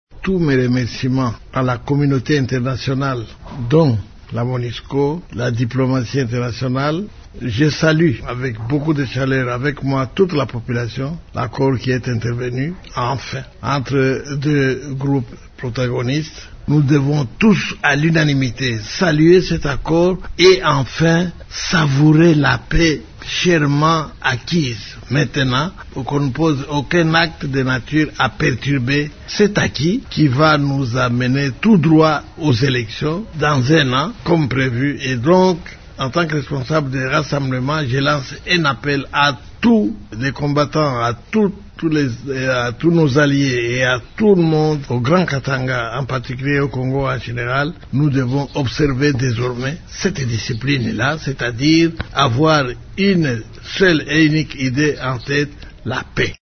Vous pouvez écouter les propos de M. Kyungu dans cet extrait sonore.